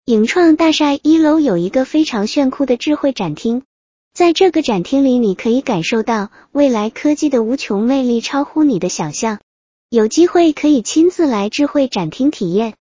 影创大厦一-迅捷文字转语音.wav